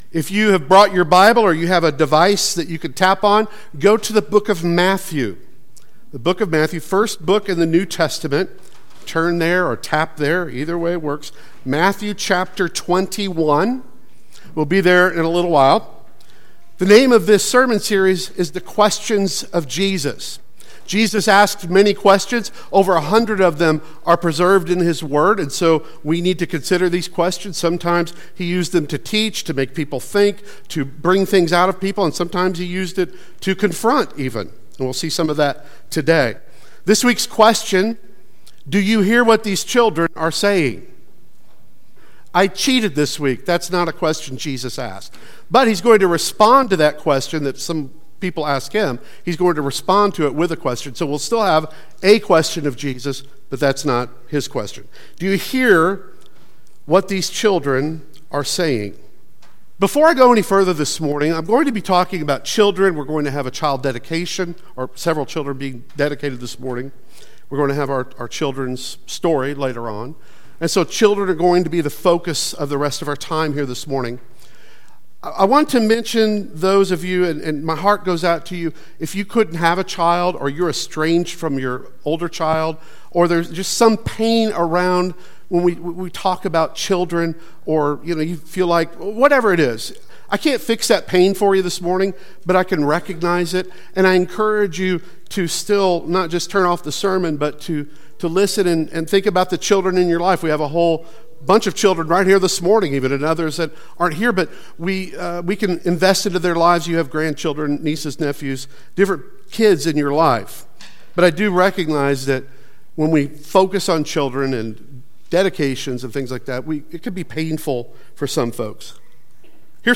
The Questions of Jesus Service Type: Sunday Worship Service Speaker